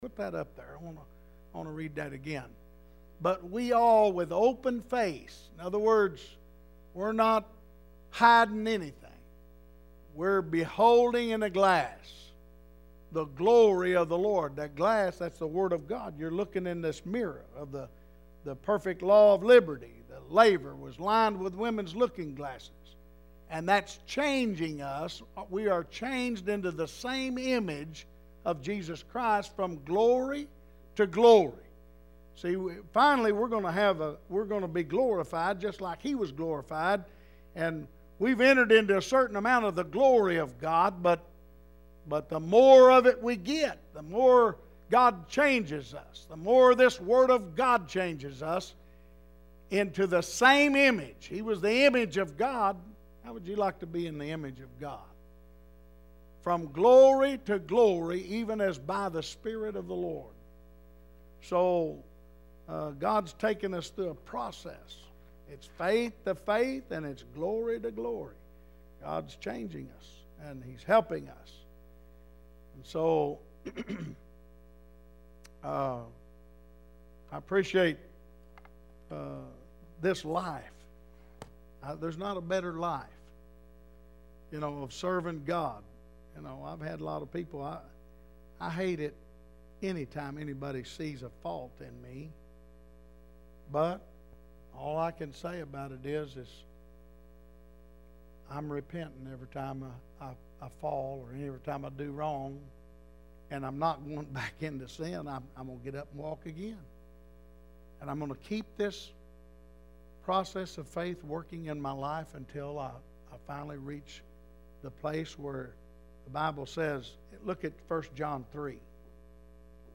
Wednesday Night Service: 101006_1A.mp3 , 101006_1B.mp3